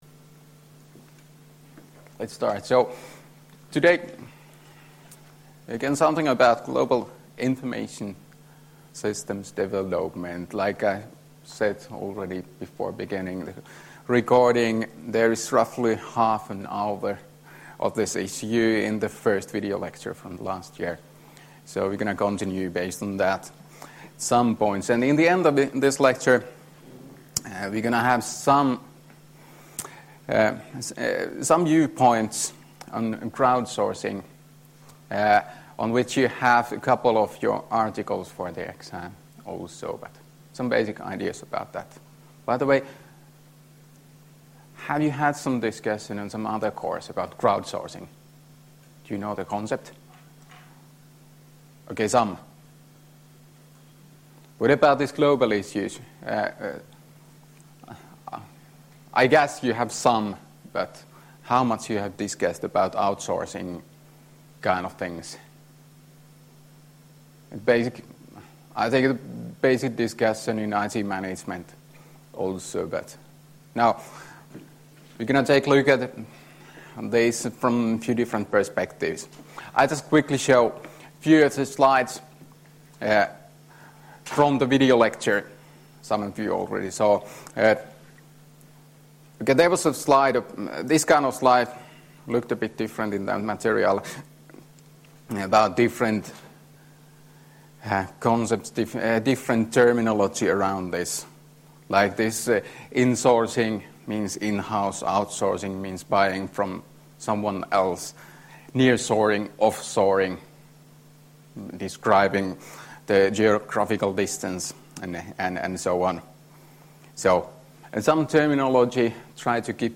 Luento 22.2.2018 — Moniviestin